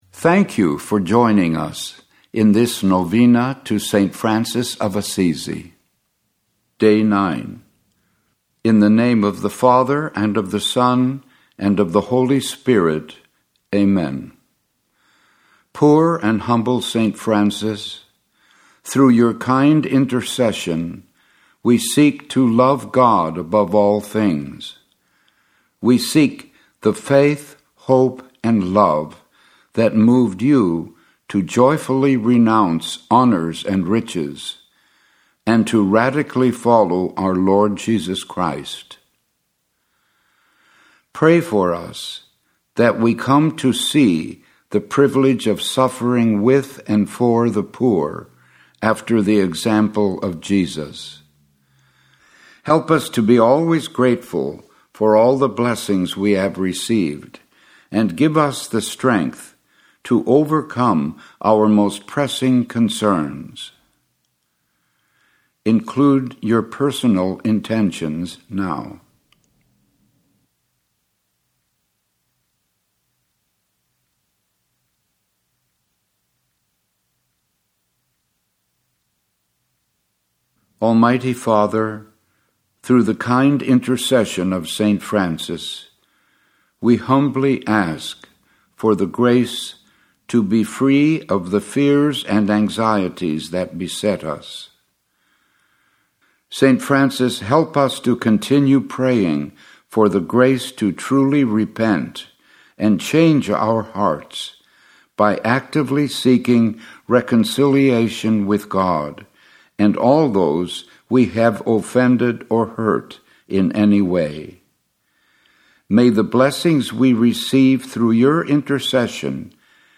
Pray the Novena to Francis of Assisi